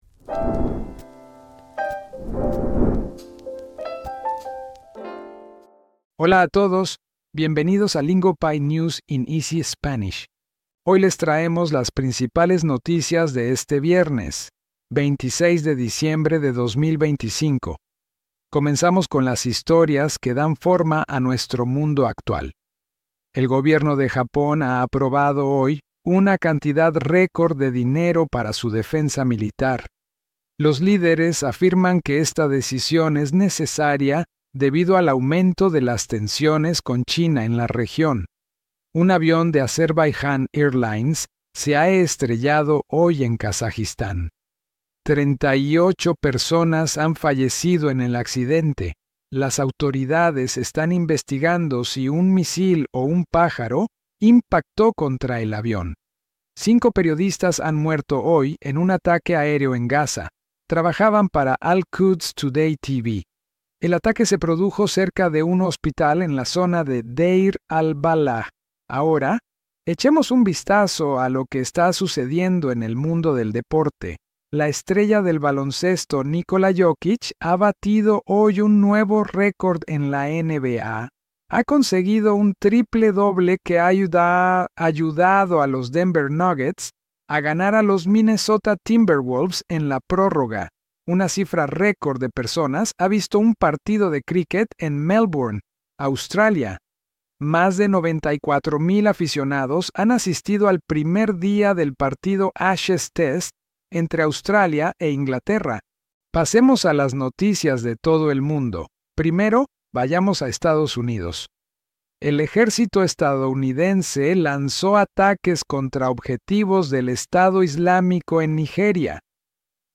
We cover what's happening around the world in clear, steady Spanish built for learners serious about improving.